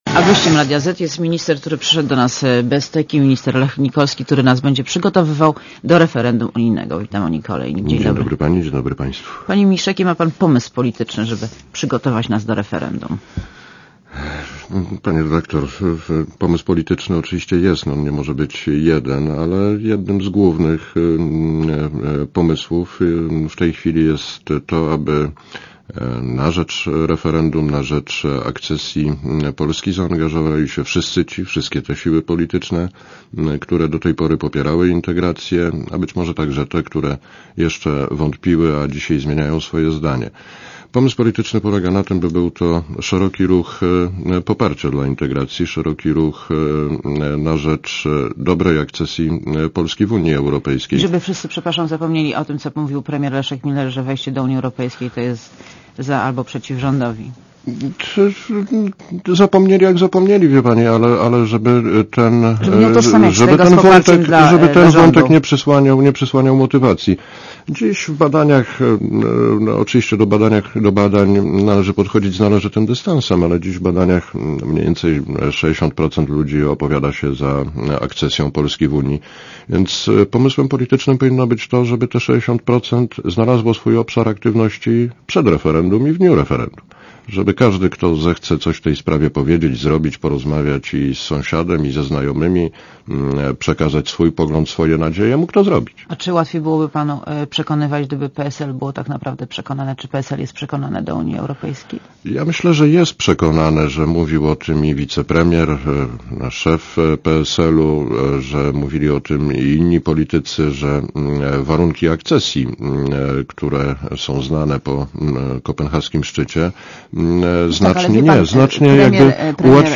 Monika Olejnik rozmawia z Lechem Nikolskim - ministrem odpowiedzialnym za przygotowanie unijnego referendum